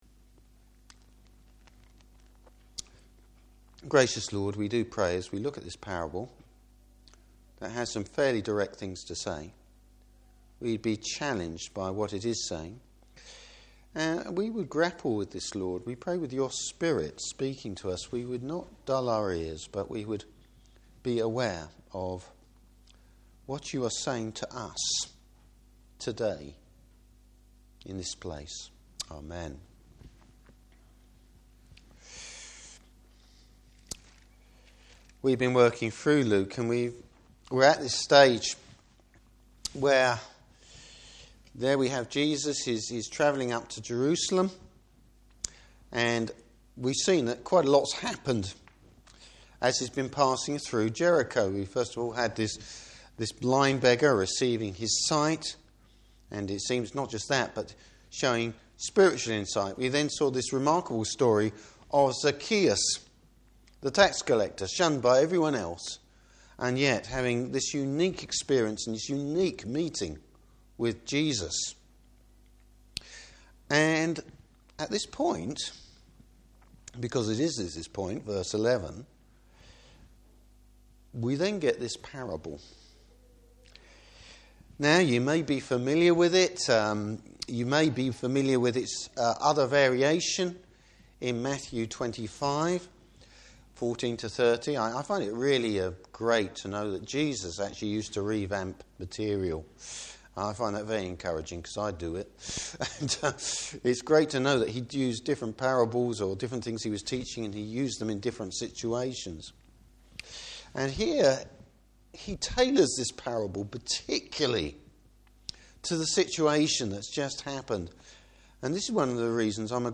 Service Type: Morning Service Bible Text: Luke 19:11-27.